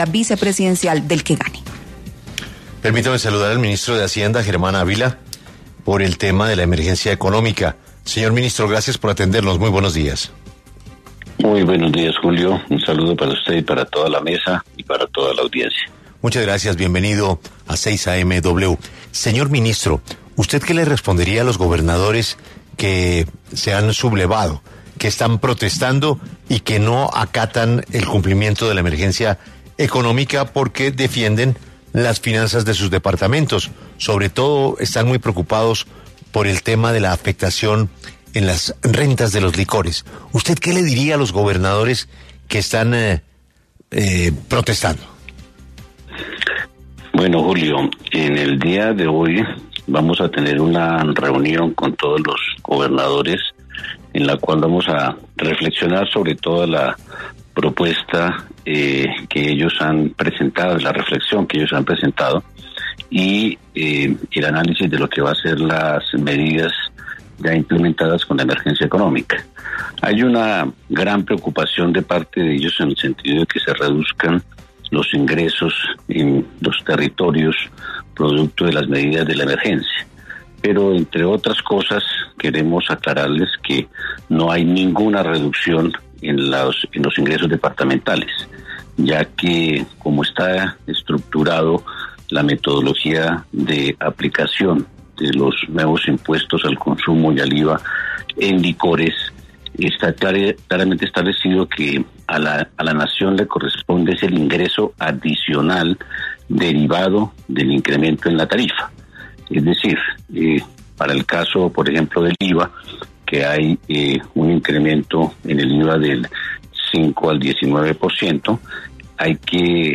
Germán Ávila anunció en primicia en 6AMW de Caracol Radio que están estudiando ese margen de reducción, pues la brecha entre el precio internacional y el precio interno se ha cerrado.